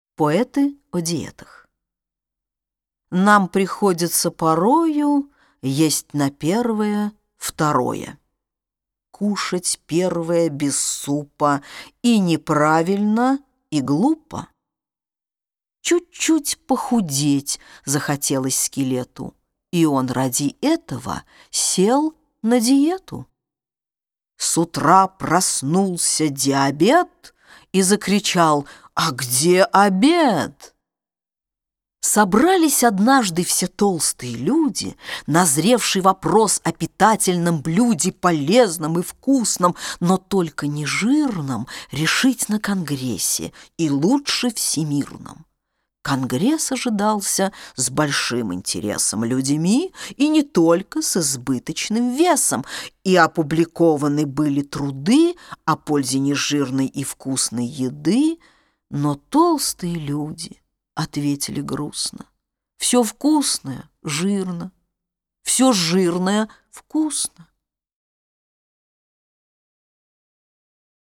Аудиокниги
Исполнитель аудиокниги: Дина Рубина